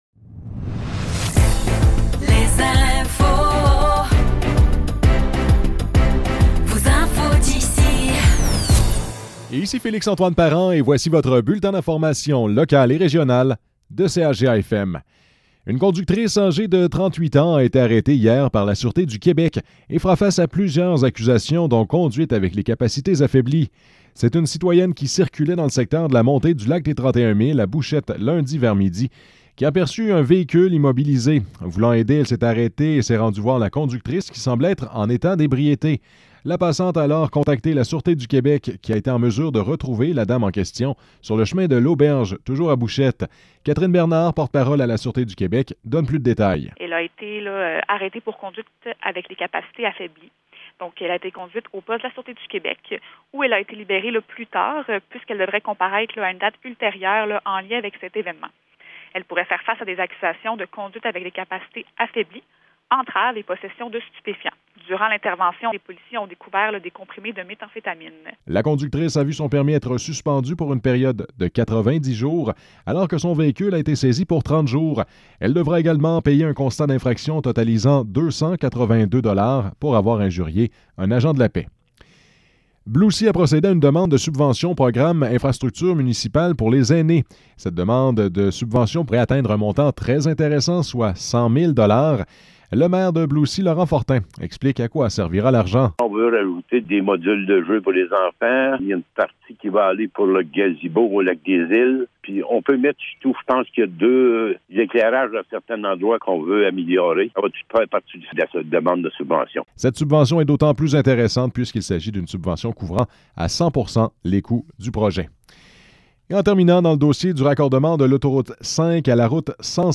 Nouvelles locales - 29 mai 2024 - 12 h